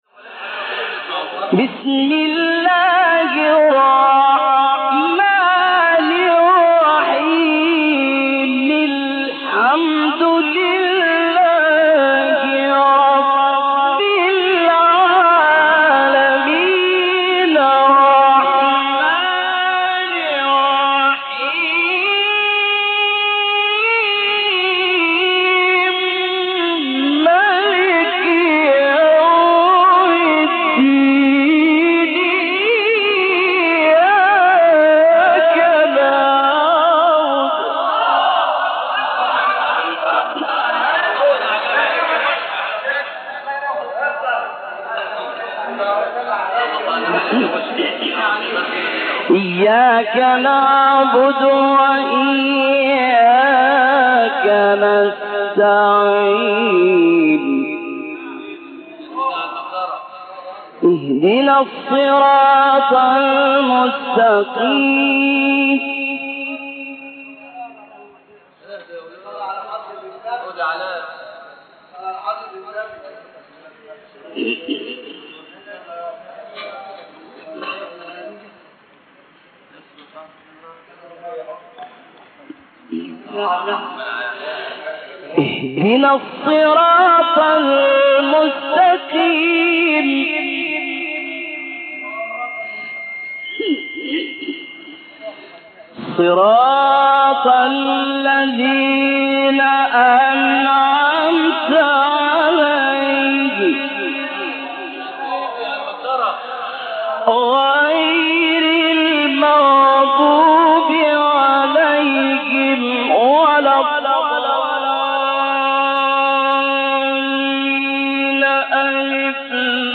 تلاوت سوره حمد و آیات ابتدایی سوره بقره استاد لیثی | نغمات قرآن | دانلود تلاوت قرآن